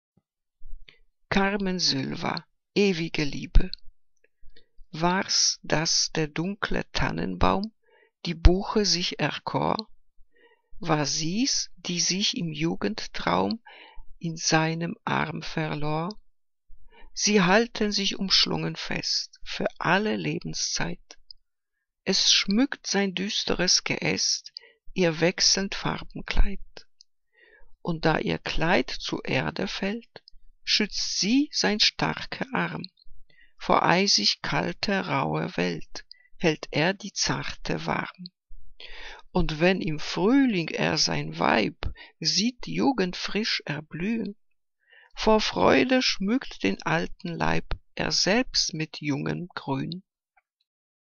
Liebeslyrik deutscher Dichter und Dichterinnen - gesprochen (Carmen Sylva)